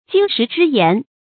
金石之言 注音： ㄐㄧㄣ ㄕㄧˊ ㄓㄧ ㄧㄢˊ 讀音讀法： 意思解釋： 比喻非常寶貴的教導或勸告 出處典故： 清 褚人獲《隋唐演義》第11回：「兄長 金石之言 ，小弟當銘刻肺腑。」